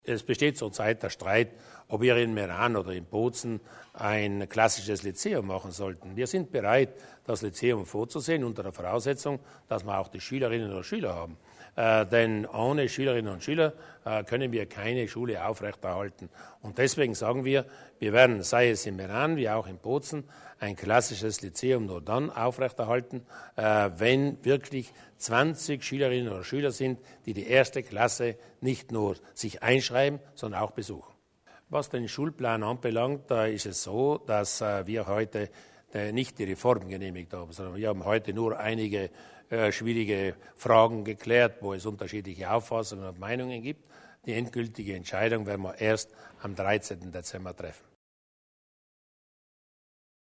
Landeshauptmann Durnwalder zu den deutschen Schulen